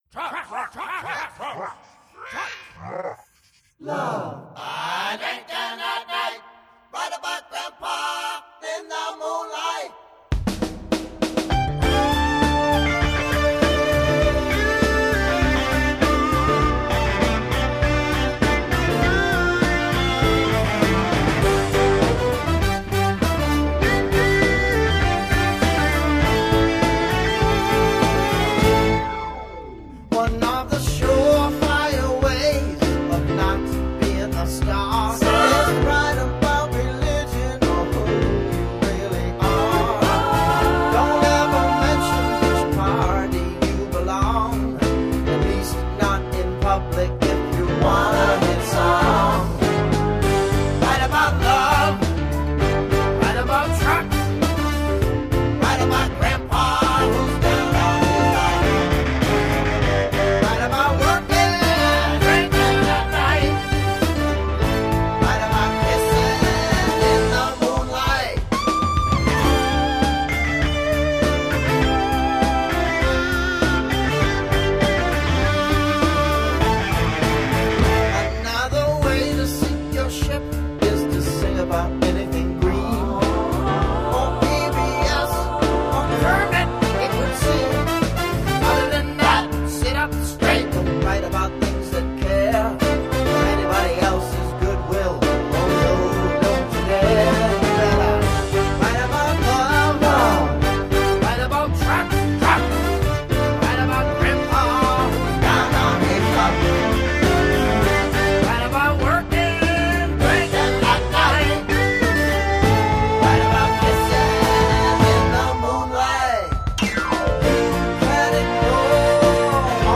Тип альбома: Студийный
Жанр: Blues-Rock, Soft-Rock